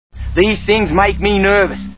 Crocodile Hunter: Collision Course Movie Sound Bites